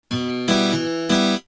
Способы аккомпанимента перебором
Em (6/8)